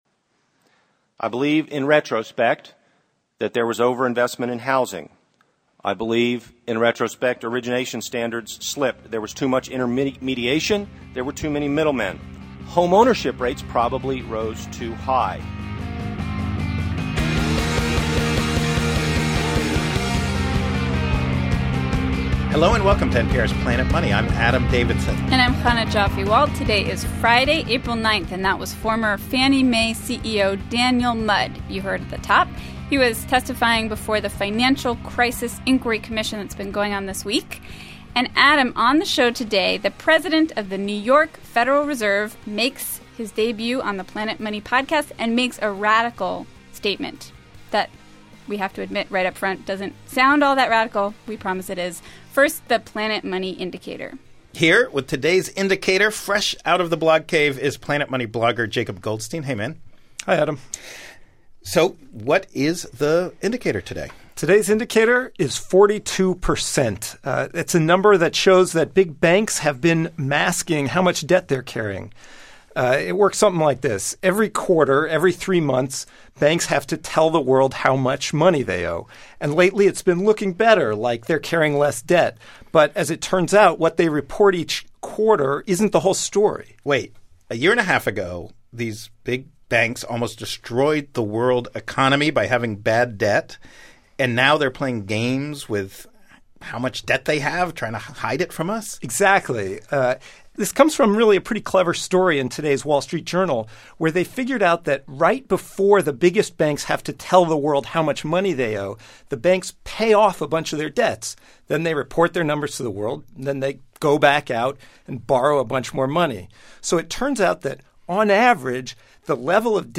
We landed a rare broadcast interview with William Dudley, the president of the New York Fed.